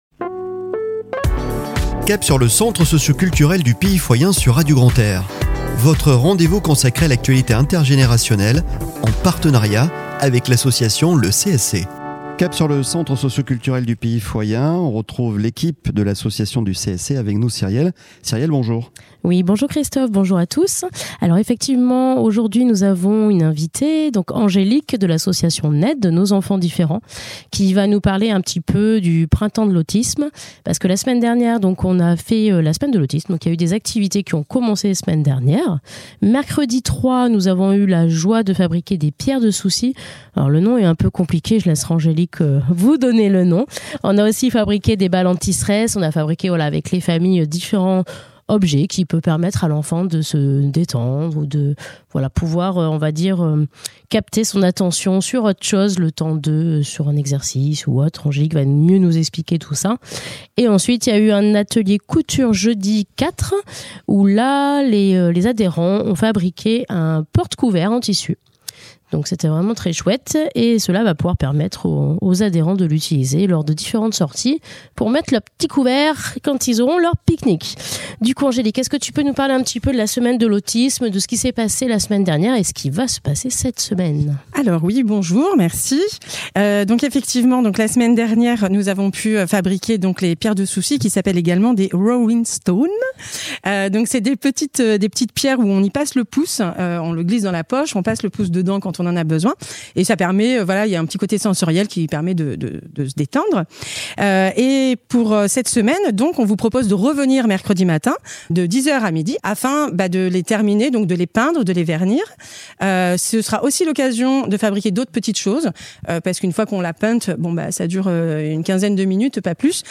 Chronique de la semaine du 08 au 13 Avril 2024 !